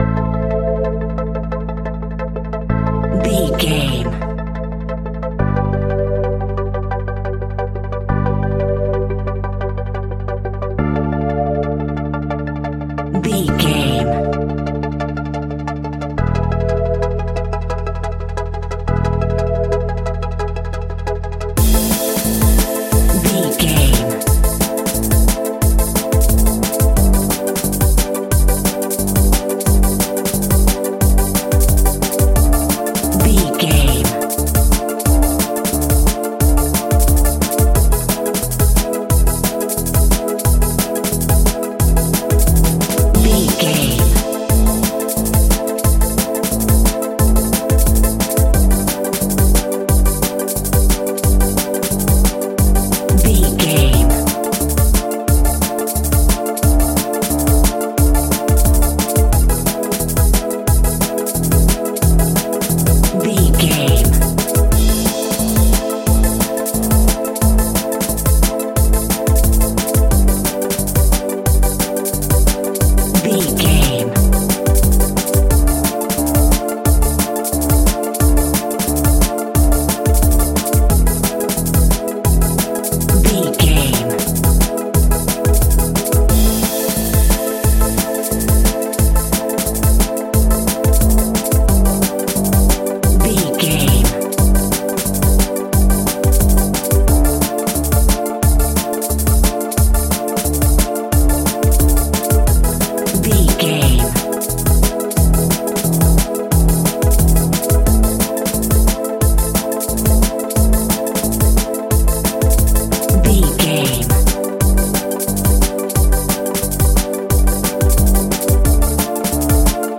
Aeolian/Minor
Fast
groovy
uplifting
futuristic
driving
energetic
repetitive
drum machine
synthesiser
organ
break beat
electronic
sub bass
synth leads
synth bass